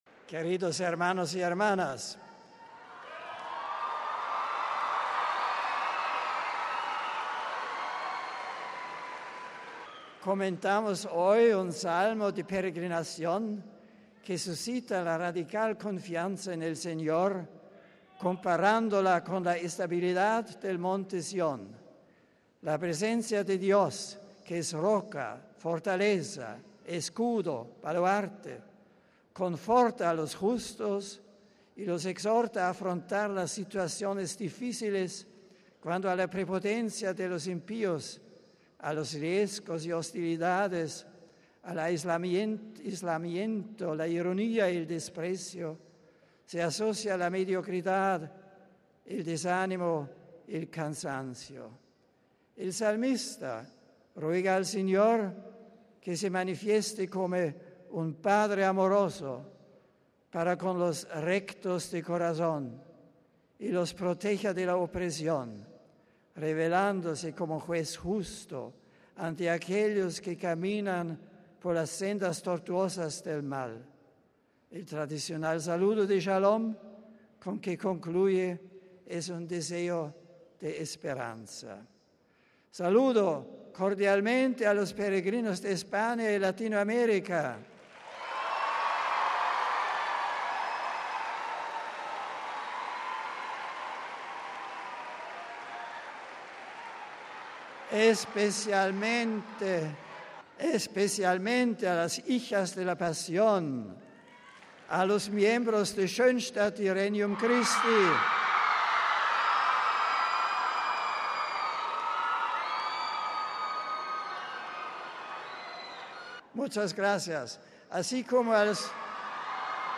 Miércoles, 3 ago (RV).- A las 10.30 de esta mañana, ante varios miles de fieles y peregrinos de todo el mundo que se hicieron presentes en el Aula Pablo VI del Vaticano, el Santo Padre Benedicto XVI reanudó las Audiencias Generales, que se habían suspendido durante el período de descanso que transcurrió el Pontífice en la región italiana del Valle de Aosta, del 11 al 28 de julio pasados.
Este fue el resumen de la catequesis que el Santo Padre leyó en español:RealAudio